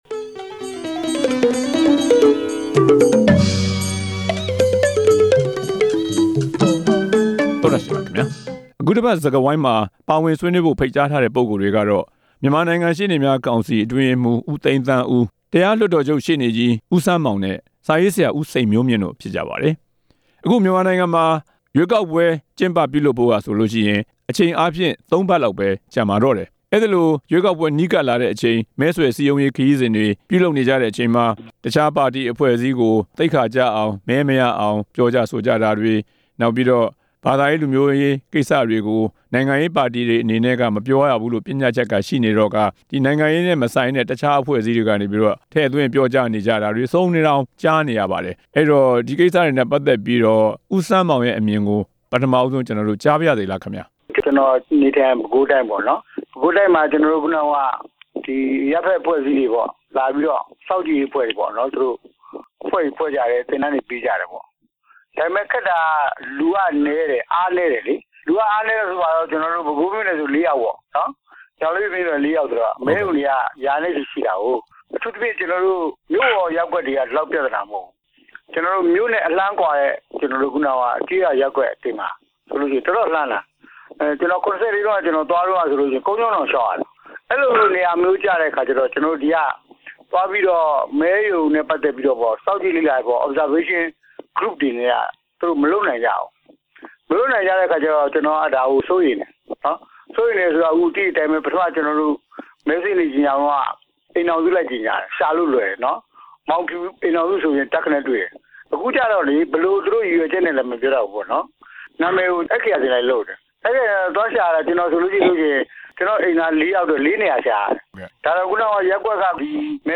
မဲဆွယ်စည်းရုံးရေးကာလ မသမာ မှုများ အကြောင်း ဆွေးနွေးချက်